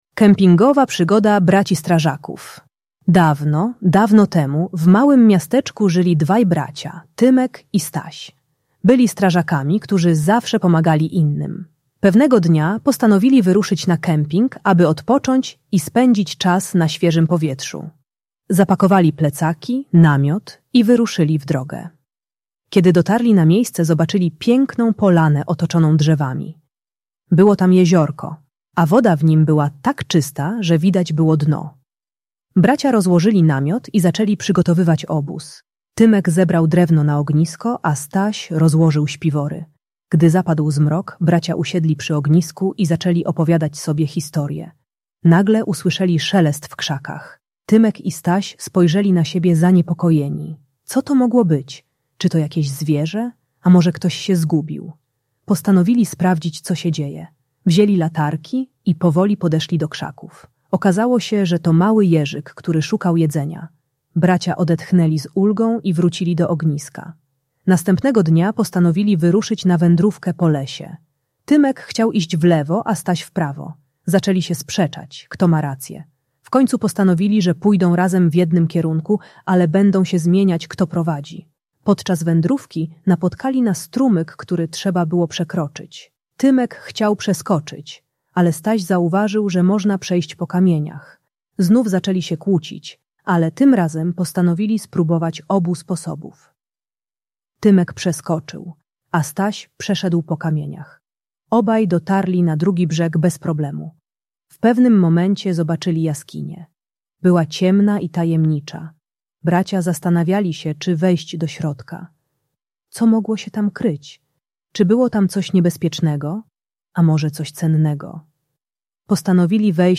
Kempingowa Przygoda Braci Strażaków - Rodzeństwo | Audiobajka